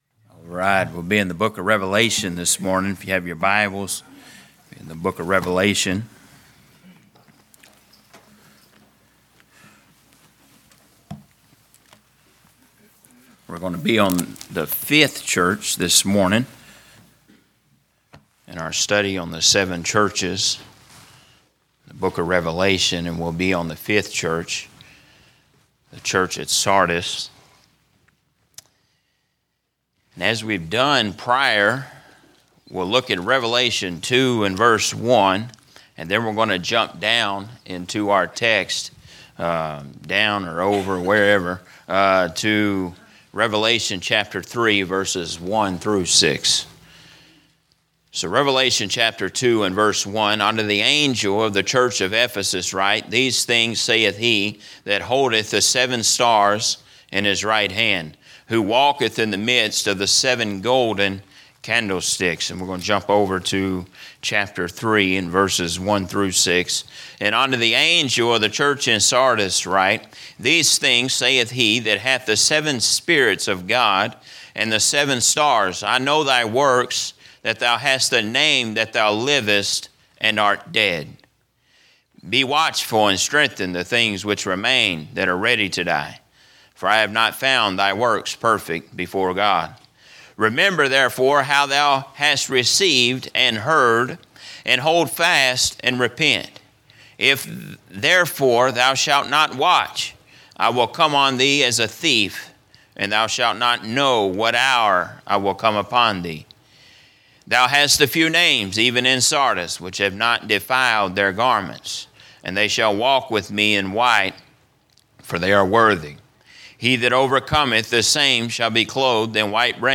A message from the series "The Seven Churches."